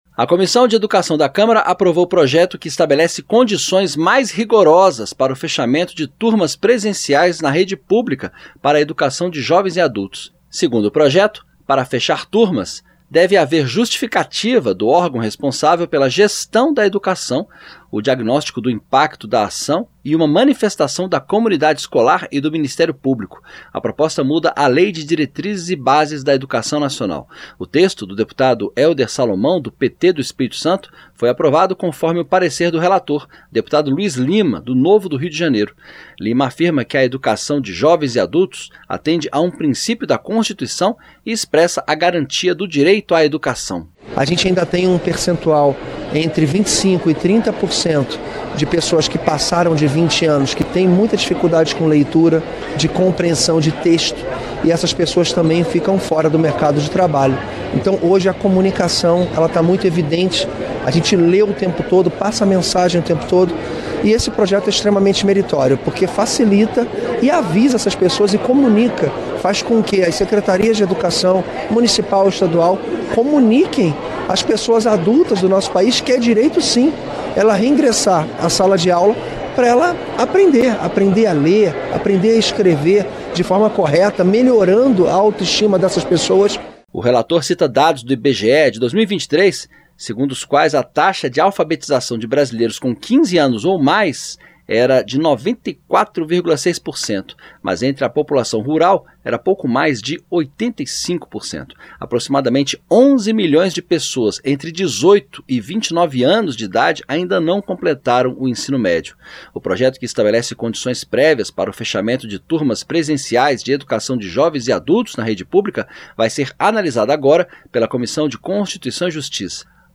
AVANÇA NA CÂMARA PROPOSTA QUE ESTABELECE CONDIÇÕES MAIS RIGOROSAS PARA FECHAMENTO DE TURMAS DE EDUCAÇÃO DE JOVENS E ADULTOS. O BRASIL AINDA TEM MAIS 10 MILHÕES DE BRASILEIROS COM IDADE ENTRE 18 E 29 ANOS QUE NÃO CONCLUÍRAM O ENSINO MÉDIO. ACOMPANHE NA REPORTAGEM